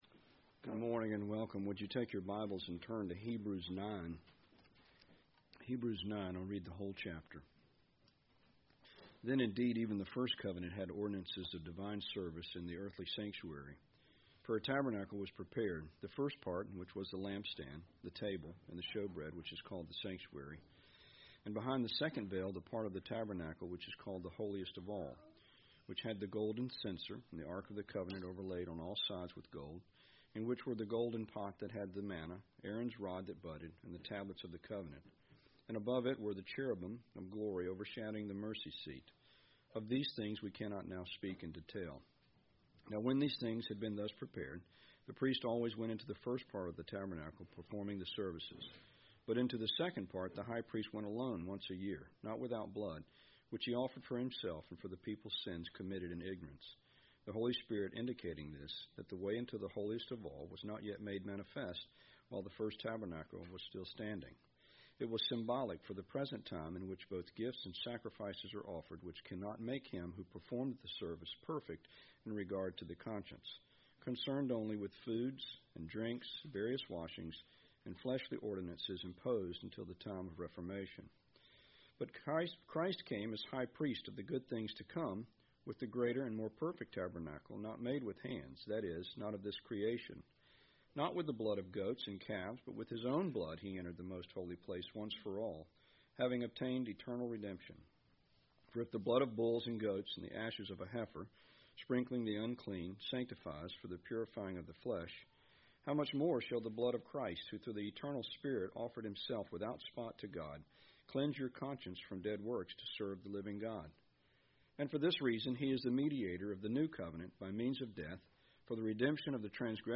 Exposition of I Peter 1:17-21